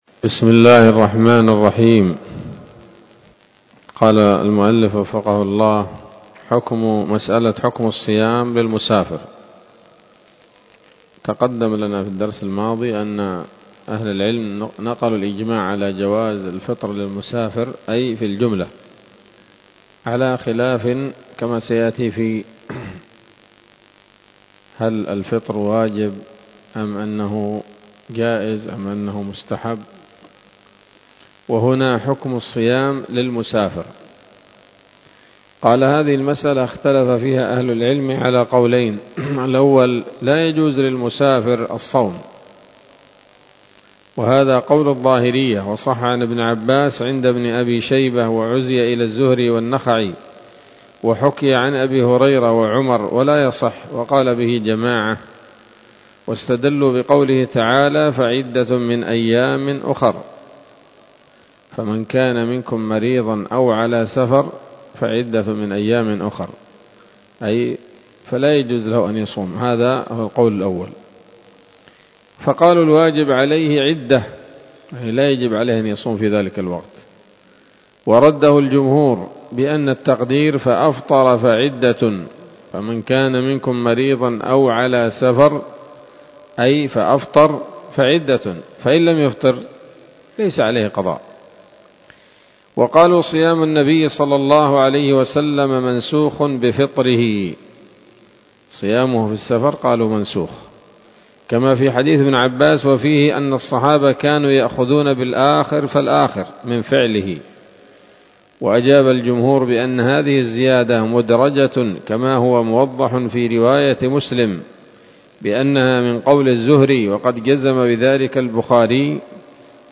الدرس الخامس عشر من كتاب الصيام من نثر الأزهار في ترتيب وتهذيب واختصار نيل الأوطار